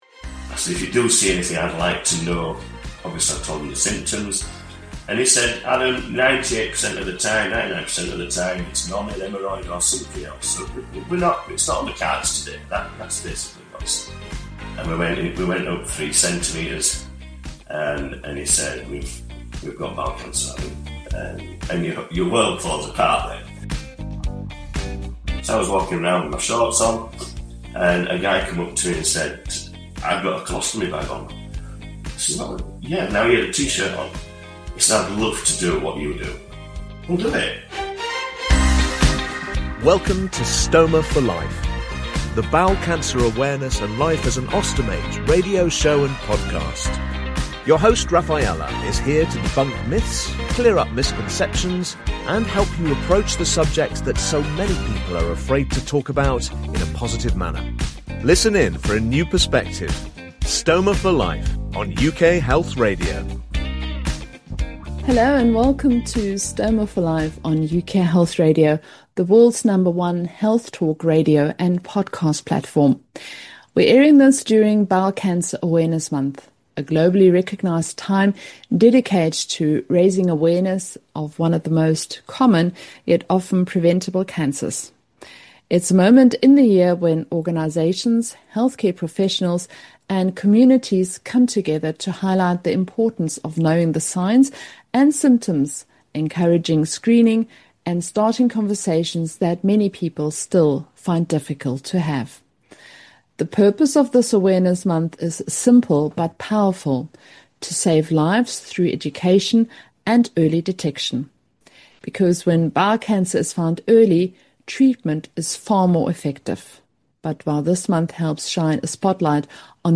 Surgeons, nurses, specialists in stoma & cancer care, IBS or Crohn's disease sufferers, people just like her, living with a stoma. She is here to debunk myths, clear up misconceptions and help you approach the subject that so many people are afraid to talk about, in a positive manner.